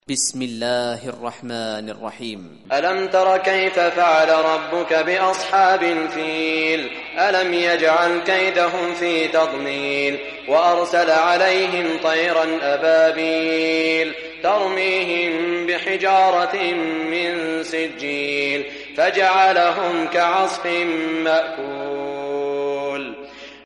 Surah Fil Recitation by Sheikh Saud Shuraim
Surah Fil, listen or play online mp3 tilawat / recitation in Arabic in the beautiful voice of Sheikh Saud Shuraim.